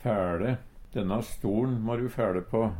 fæLe - Numedalsmål (en-US)